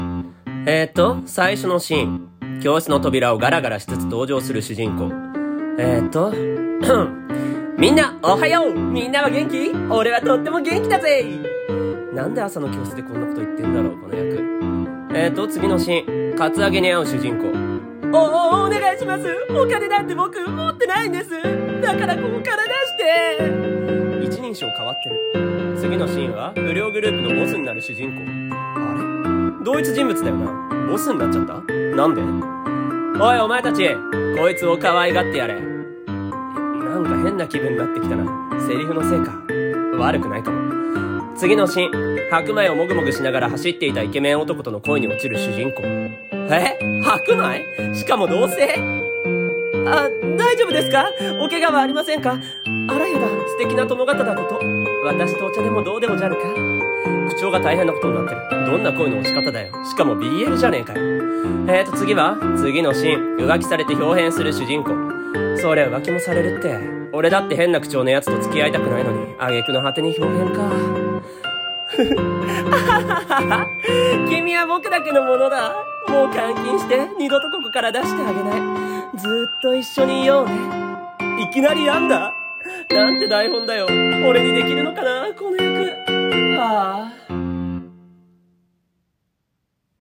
【ギャグ台本】場面転換【一人声劇】 / 声劇